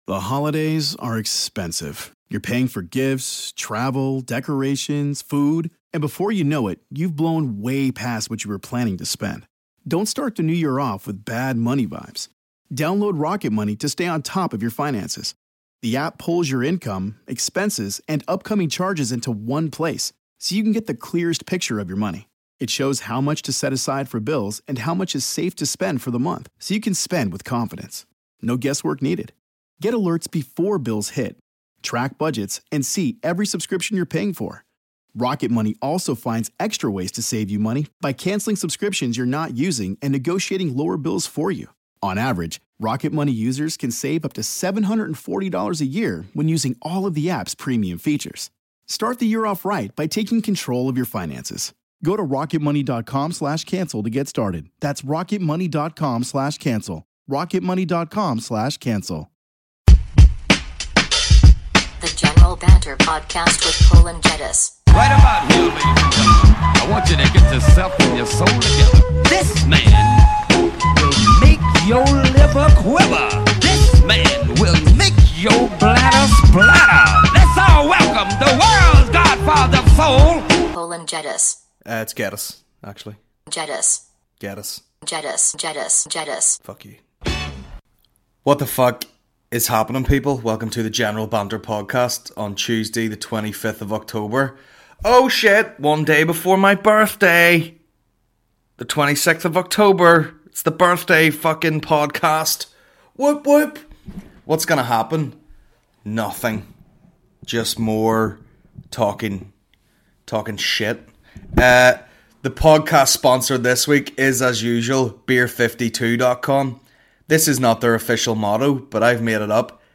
Comedy podcast